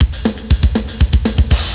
batterie.zip 1.4 Mo 18 virgules sonores de batterie.
Batterie [cliquez pour écouter] 14 ko